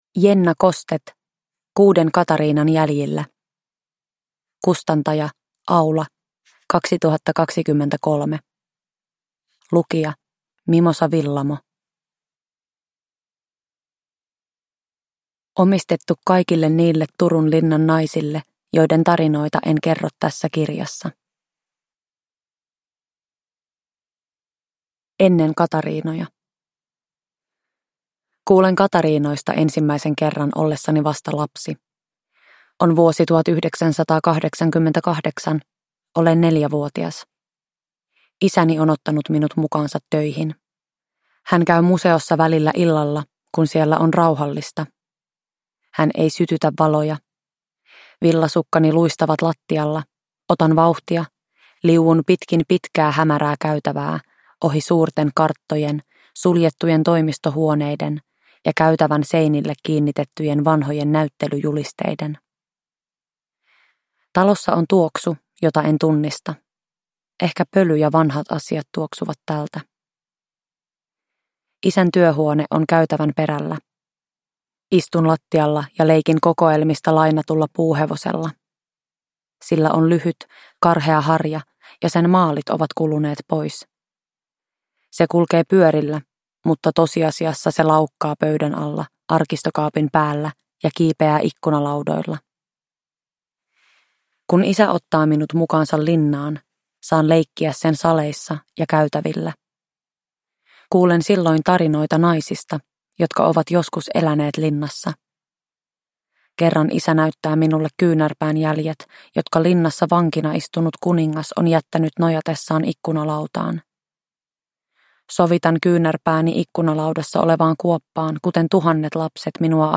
Kuuden Katariinan jäljillä (ljudbok) av Jenna Kostet